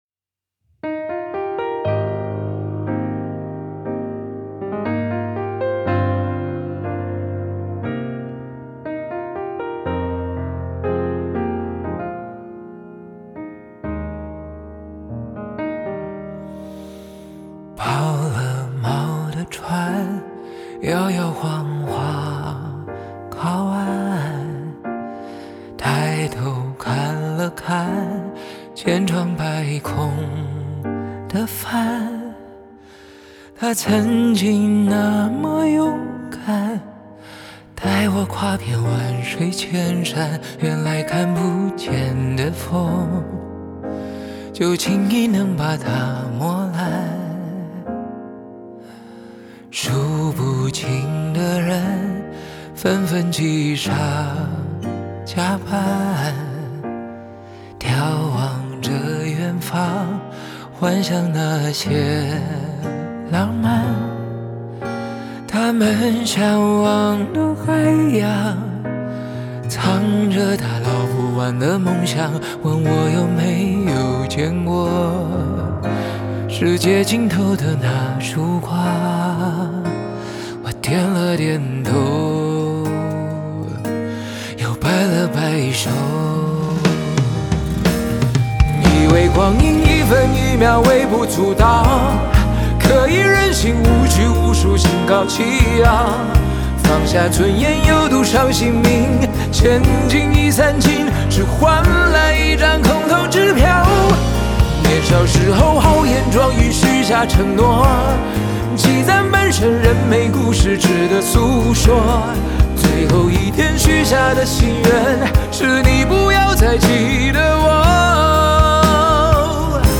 Ps：在线试听为压缩音质节选，体验无损音质请下载完整版
吉他
弦乐团